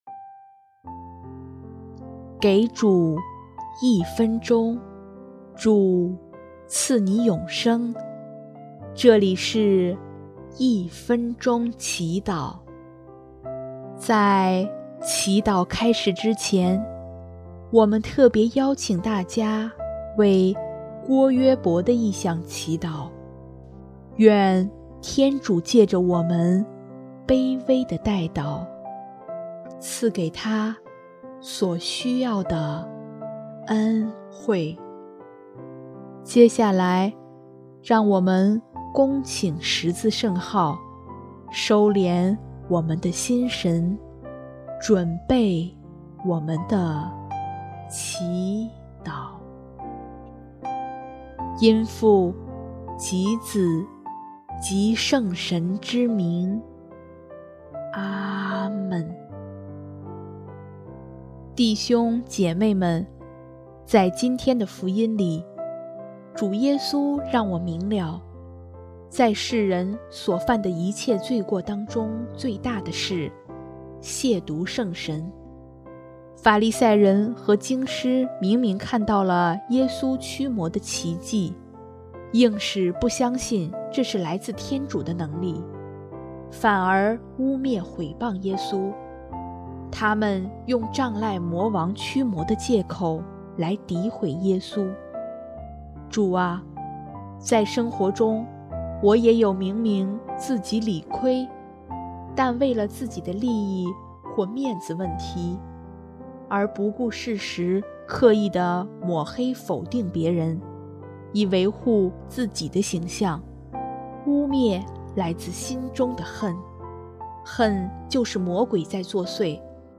音乐： 第三届华语圣歌大赛参赛歌曲《献上一切》